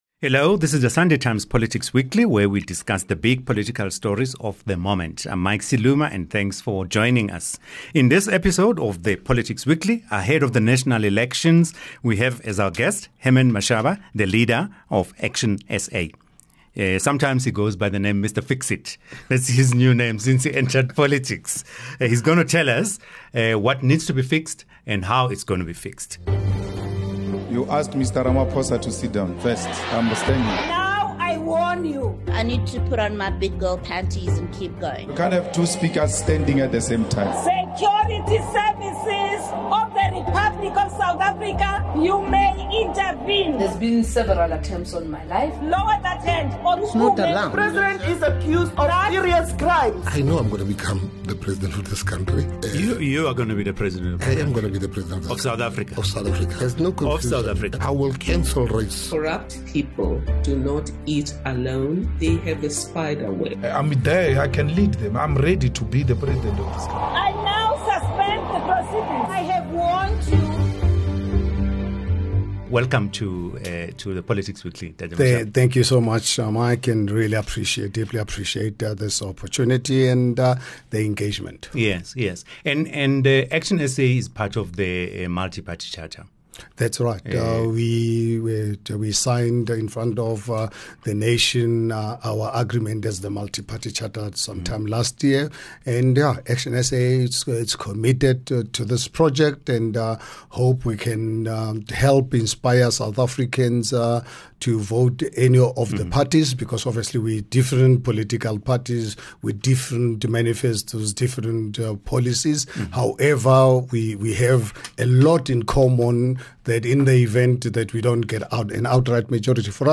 The DA should have burnt the ANC flag instead of the national flag, says ActionSA leader Herman Mashaba says in a wide-ranging interview on the Sunday Times Politics Weekly podcast.